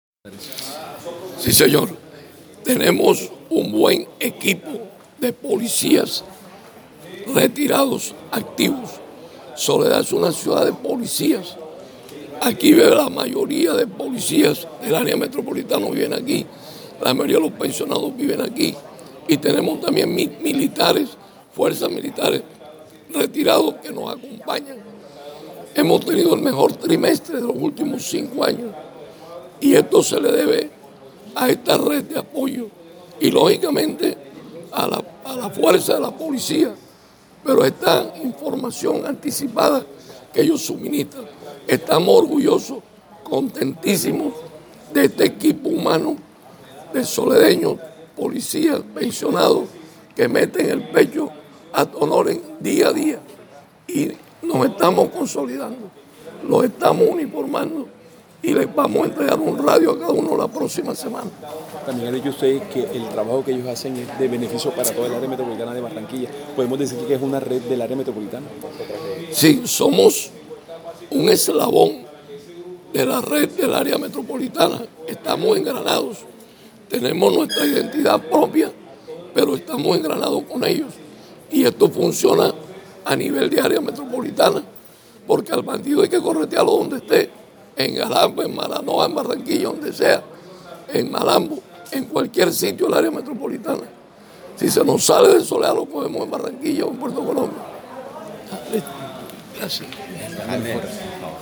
Audio-Alcalde-Joao-Herrera.mp3